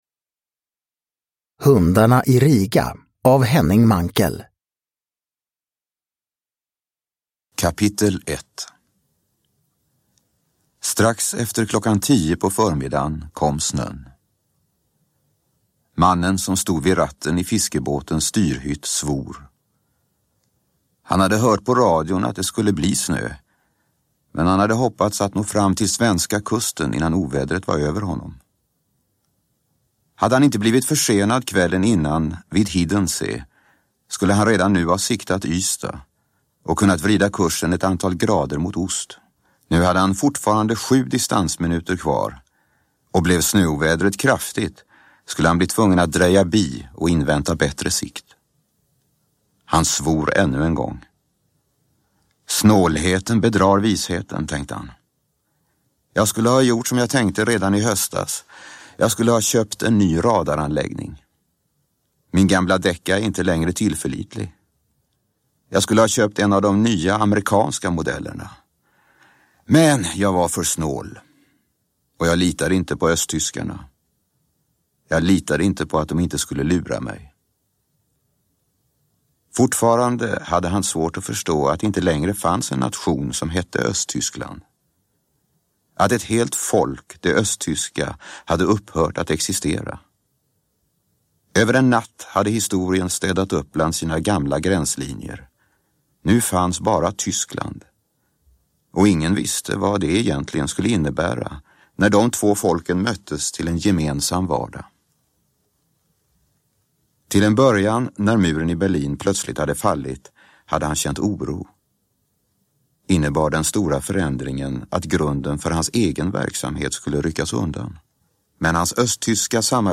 Hundarna i Riga – Ljudbok
Hundarna i Riga är andra boken i den berömda serien om kommissarie Kurt Wallander. Här i Reine Brynolfssons originalinspelning.
Uppläsare: Reine Brynolfsson